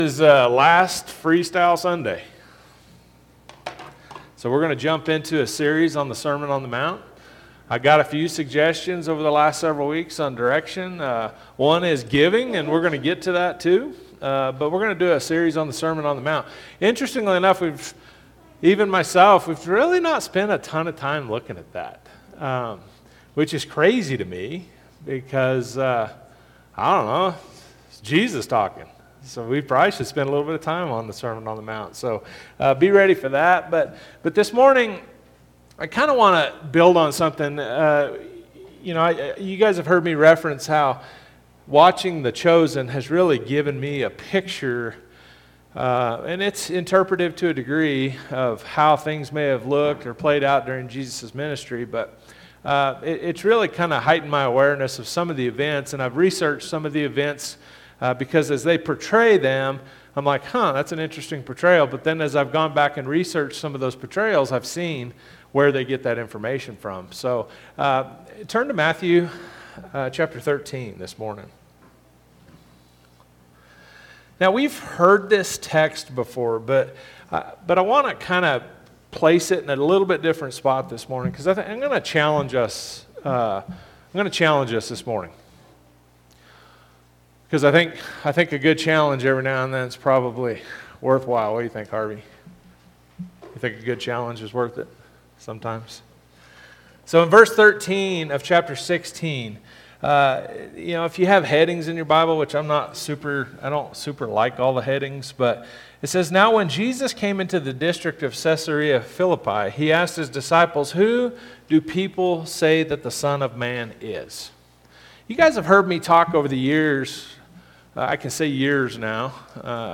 Sunday AM sermon